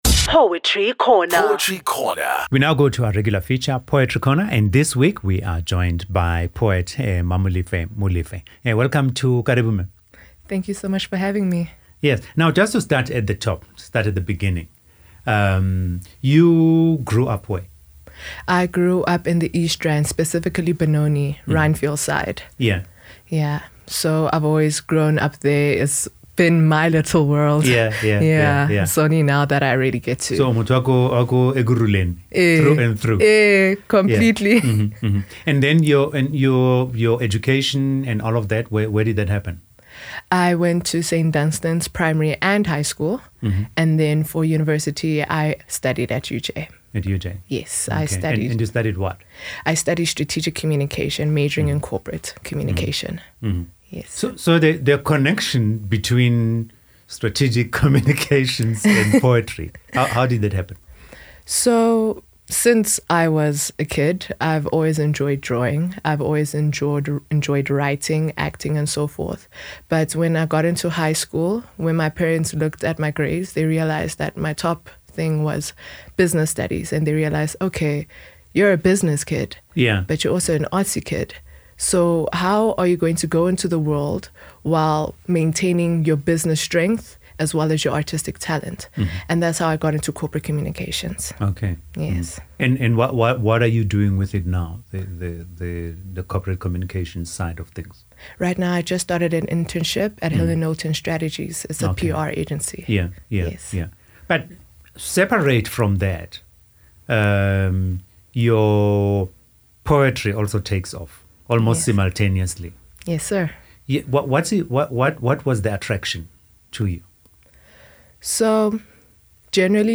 Poetry Interviews and Performances
In February of 2020, I was invited onto KayaFM's Karibu Poetry Corner.